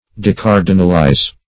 Decardinalize \De*car"di*nal*ize\, v. t. To depose from the rank of cardinal.